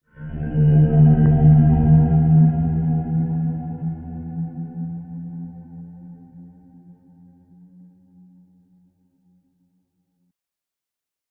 Alien 2
alien-2.mp3